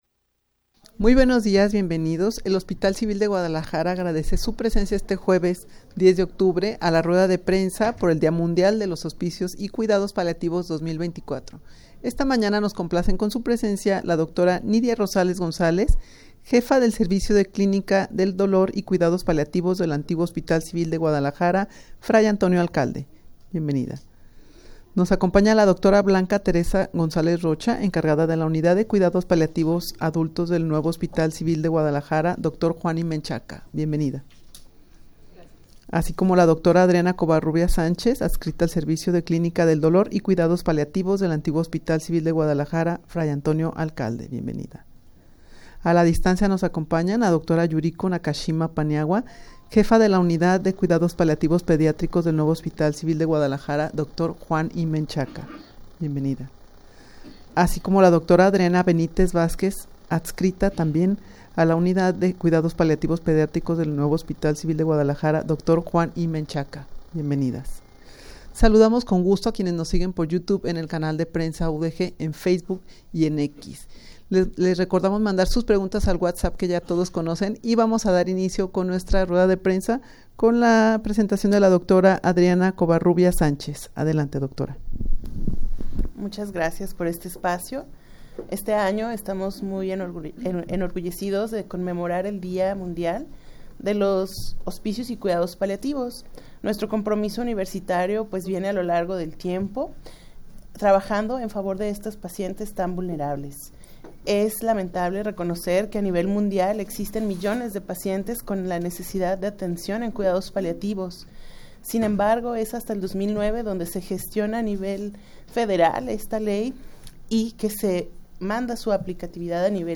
Audio de la Rueda de Prensa
rueda-de-prensa-por-el-dia-mundial-de-los-hospicios-y-cuidados-paliativos-2024.mp3